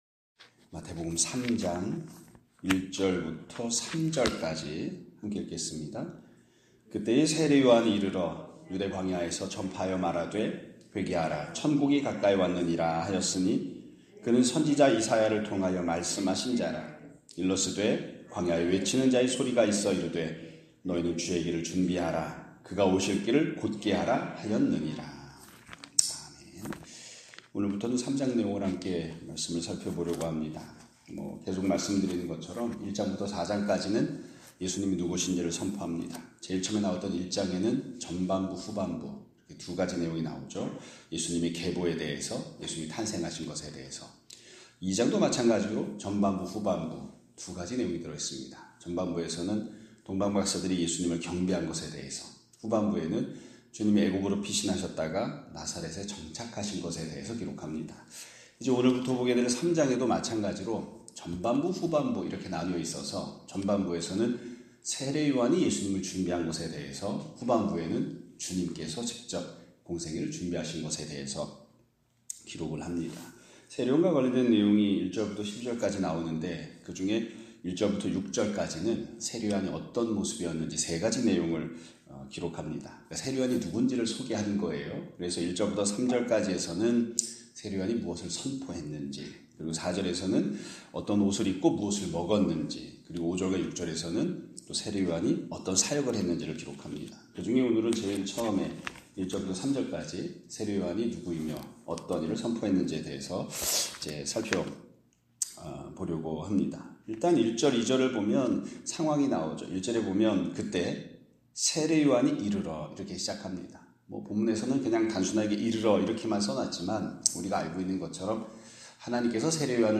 2025년 4월 10일(목요일) <아침예배> 설교입니다.